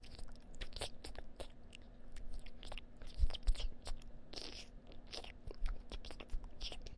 外星人吃东西
描述：一个decrackle过滤器 只留下噼啪声，发出这种奇怪的声音......
Tag: 进食 咀嚼 外来